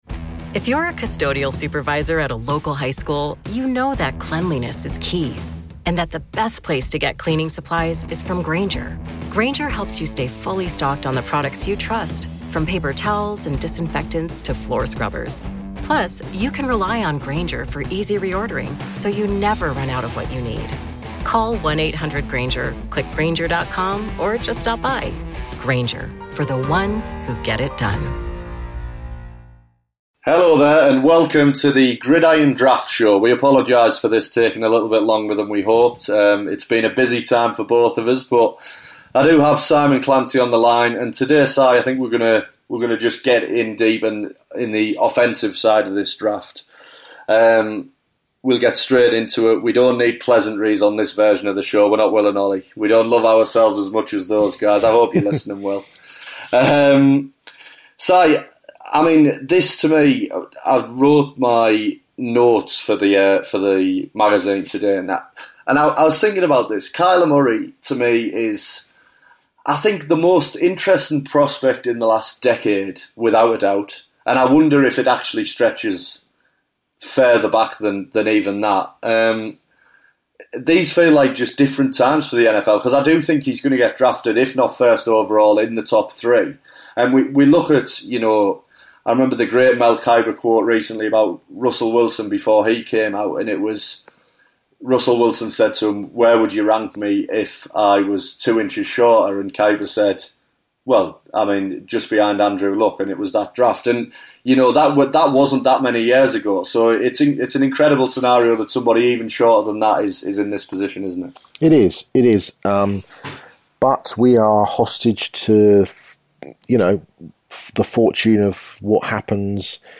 Draft Pod two features our radio row interview with the number 1 Running Back in the draft Josh Jacobs from Alabama, talking brilliantly about Nick Saban, the Crimson tide and what teams can expect from him.